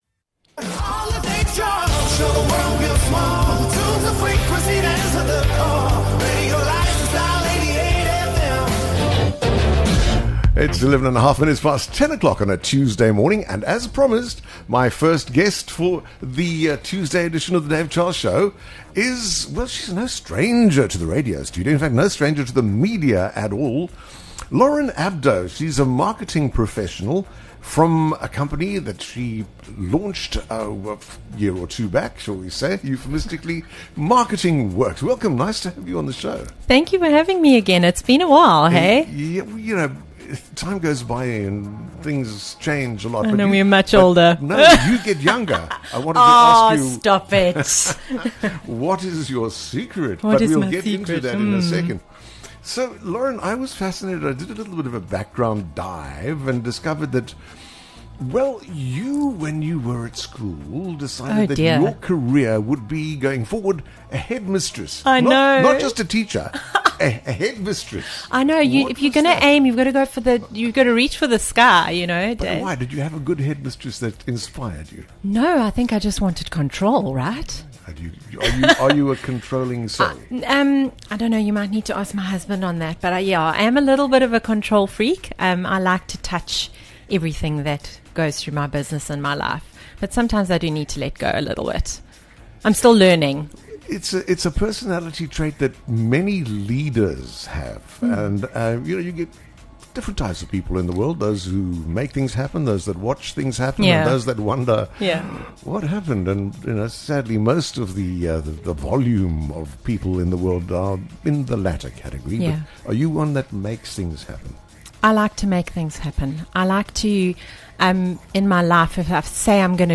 Whether it’s your favourite songs, meaningful milestones, or the moments that shaped you, come and share them live on air.